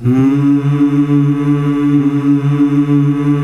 MMMMH   D.wav